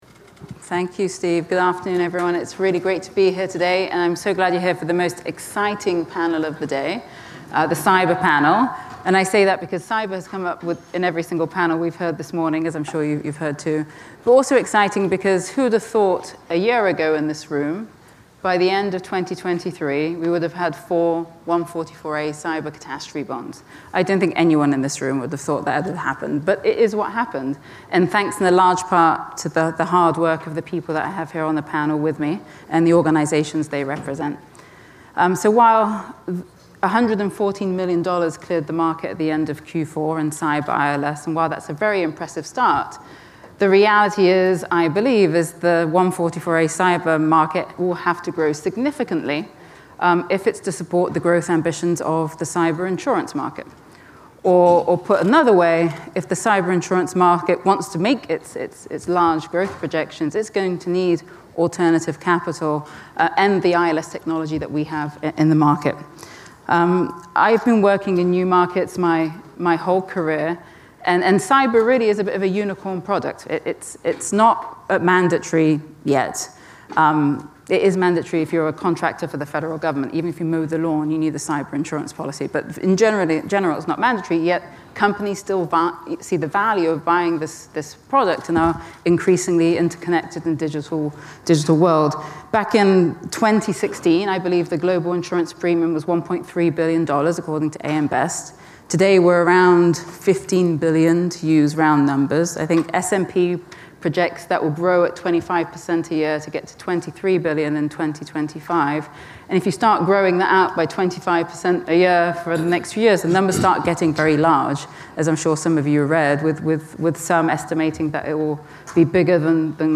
This fourth podcast from our ILS NYC 2024 conference features a panel discussion focused on cyber risk and looks ahead to the potential for cyber catastrophe bond activity to grow, titled: Cyber cat bonds: After a successful start, where next?
This panel discussion was the fourth session of the day at our Artemis ILS NYC 2024 conference, held in New York on February 9th 2024.